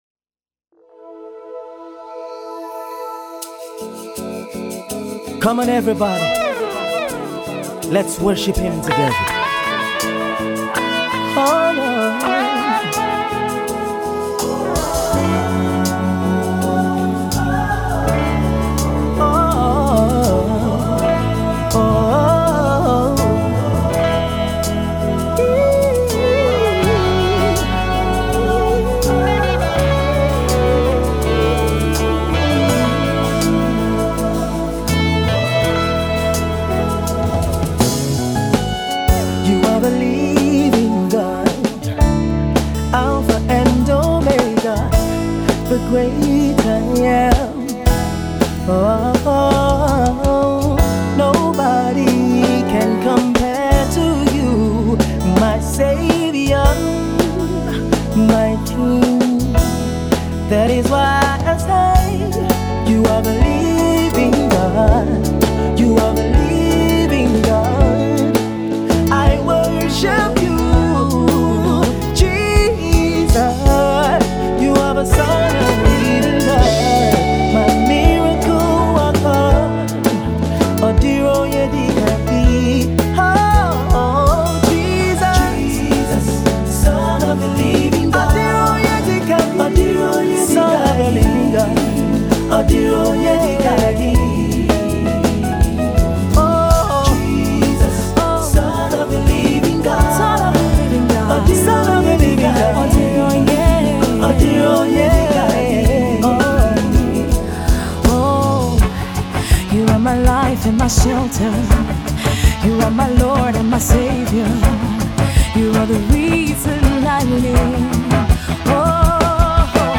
passionate piece